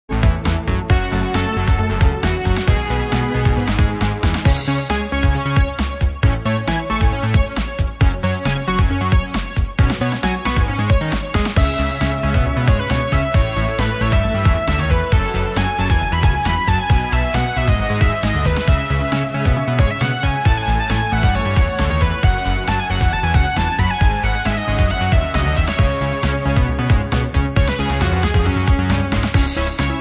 Fusion Remix